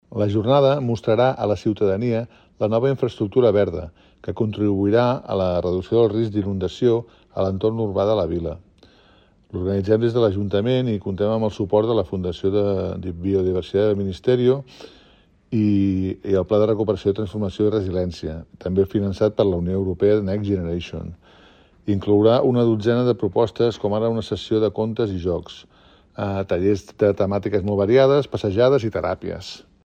Jordi Amat, regidor de Medi Ambient i Benestar Animal de l'Ajuntament